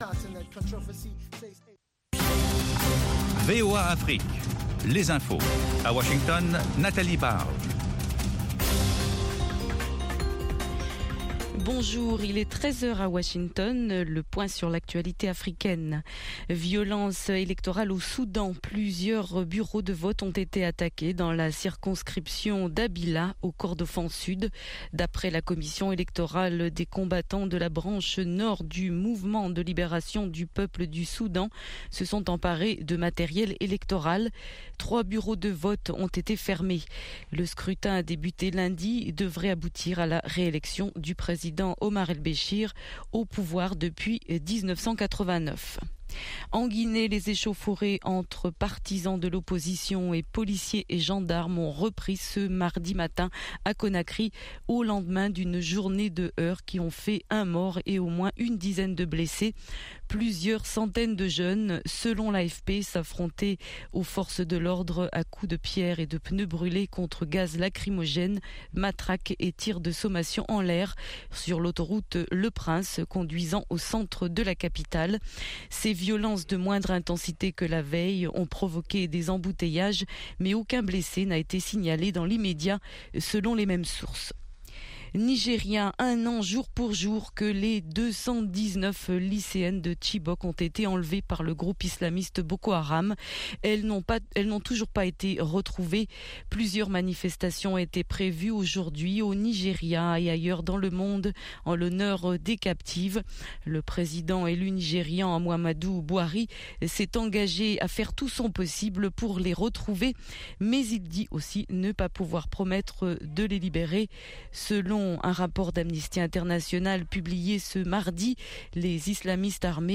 10 min News French